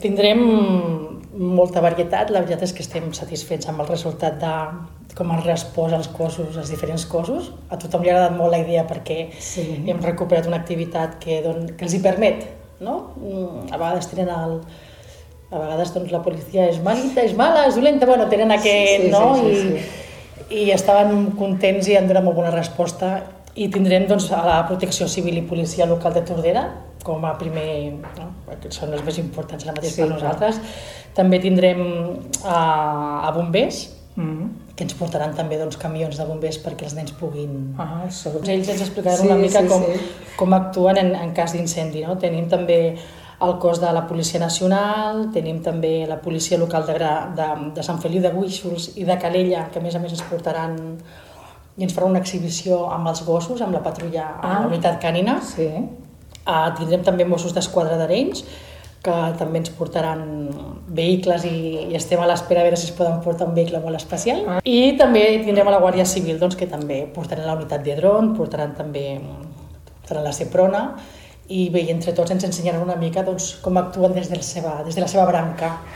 Ho explica la regidora de seguretat, Nàdia Cantero.